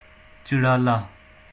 pronunciation] is a village and Union Council of Dhirkot Tehsil in the Bagh District of Azad Kashmir.[1]
ChiralaSound.ogg.mp3